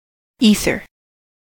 ether: Wikimedia Commons US English Pronunciations
En-us-ether.WAV